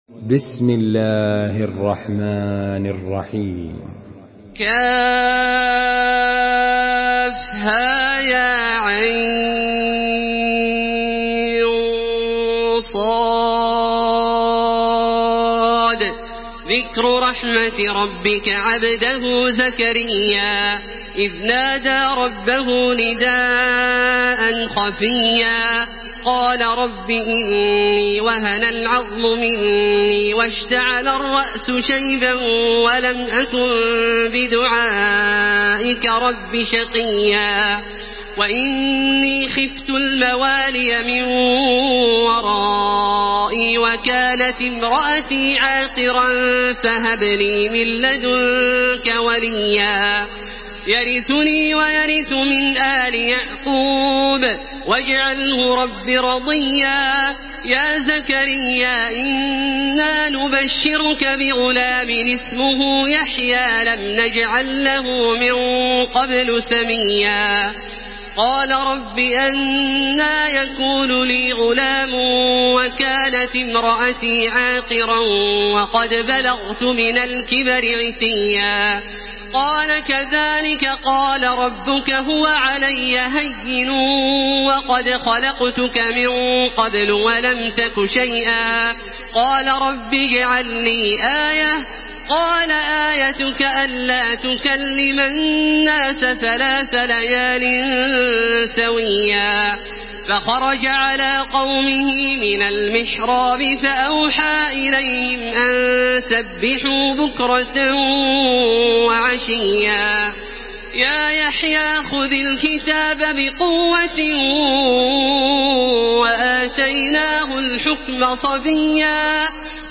Surah مريم MP3 by تراويح الحرم المكي 1433 in حفص عن عاصم narration.
مرتل